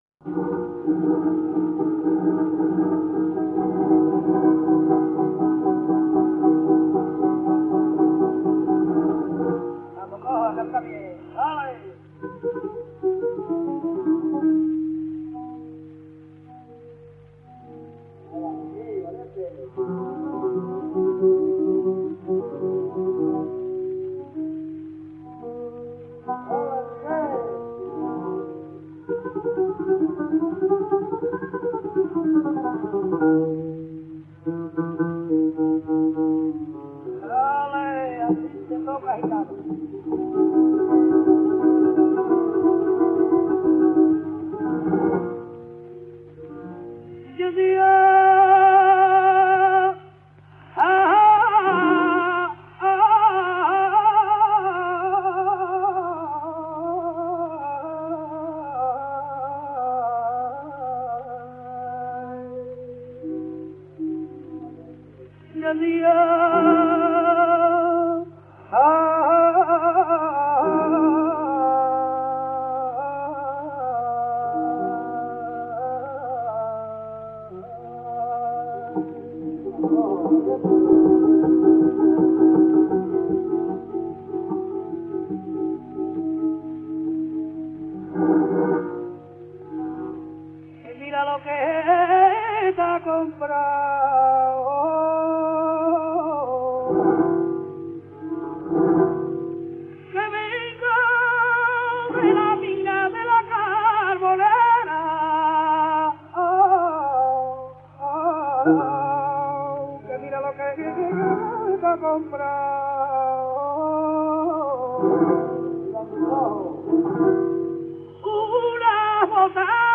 Surtout, son style vocal singulier rend ses interprétations fondamentalement originales, quel que soit le modèle de référence : sur le plan mélodique, un usage intensif des notes de passage chromatiques, souvent accompagnées de portamentos ; sur le plan ornemental, un continuum vibrato élargi/mélismes, le passage de l’un aux autres étant souvent indiscernable — de plus, contrairement à l’usage, ses mélismes plongent fréquemment sous la note porteuse, et procèdent parfois par notes disjointes, tempérées ou non.
levantica_2_taranta_del_cojo_1921.mp3